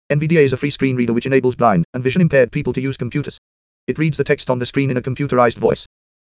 Samples of Colibri and High-Quality voices
The same text will be read by HQ voices and by Colibri voices. Colibri voices will read the text with a higher speech rate to comply with the suggested application, however the user has complete control of the speech parameters, so that even Colibri voices can be used at lower speed, and high-quality voices at faster speed.